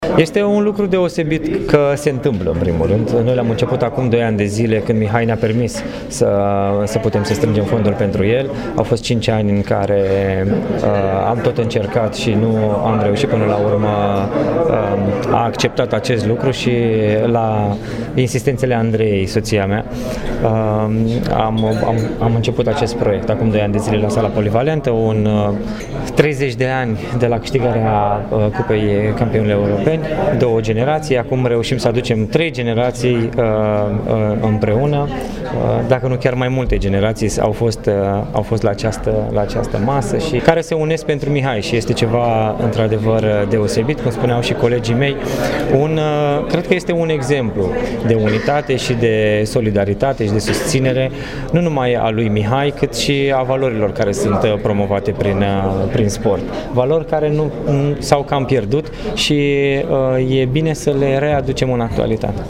George Ogăraru, fost jucător la CSM Reșița și coleg cu Neșu la Steaua București, a vorbit despre evenimentul de la Oradea:
F6-Ogararu-organizator-Gala-Nesu.mp3